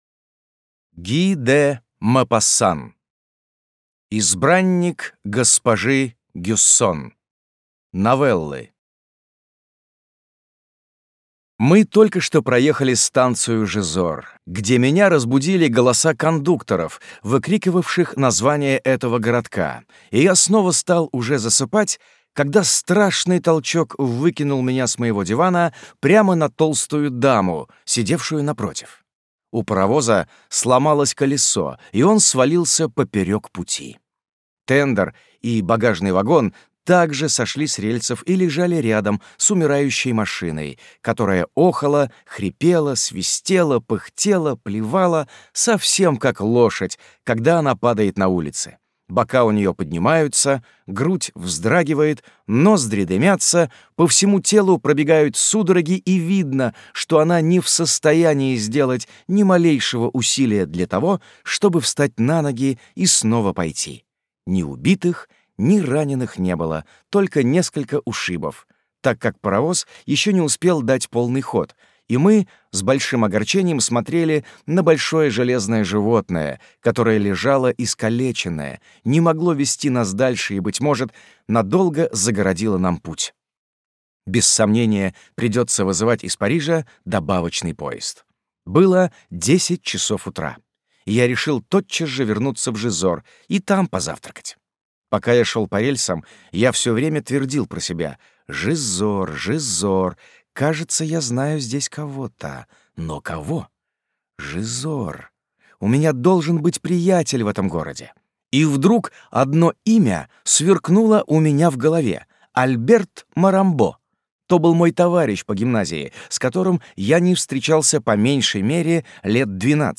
Аудиокнига Избранник госпожи Гюссон. Новеллы | Библиотека аудиокниг